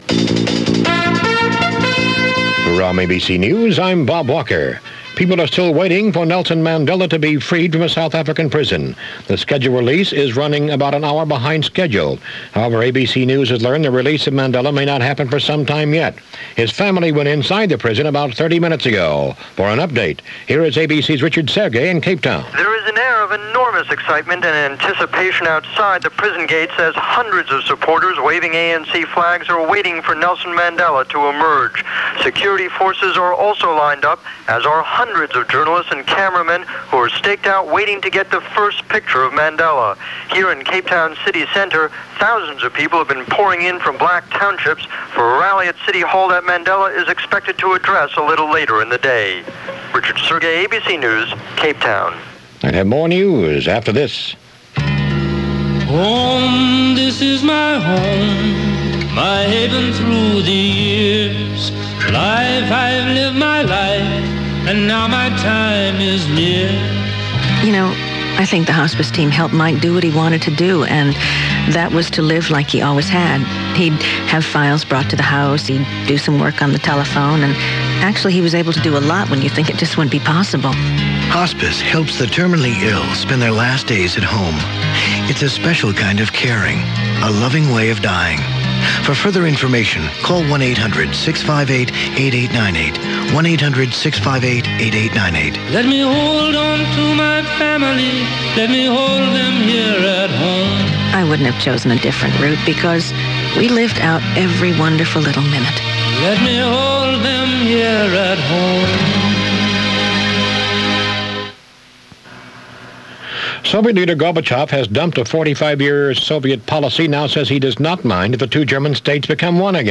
February 11, 1990 – ABC Radio News On The Hour – CBS Radio Special Report